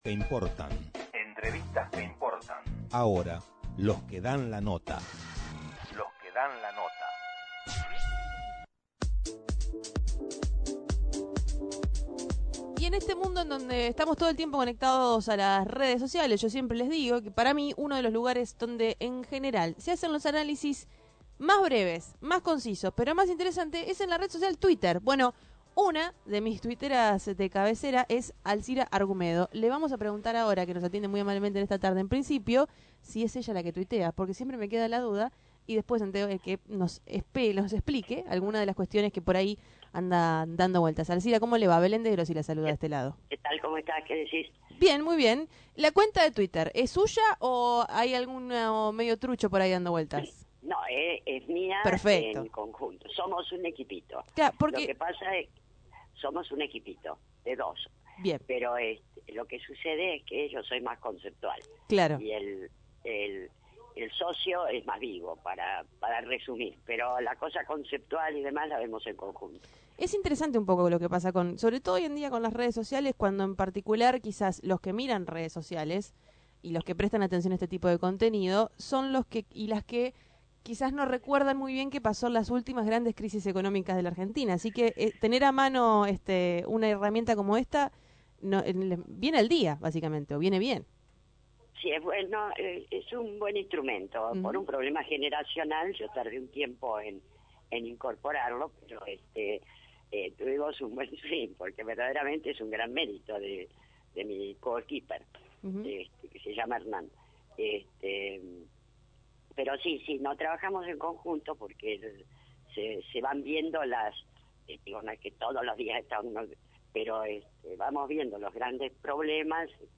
Tren Urbano dialogó con la socióloga y ex legisladora Alcira Argumedo sobre la coyutura económica.